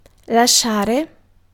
Ääntäminen
US : IPA : [leɪ]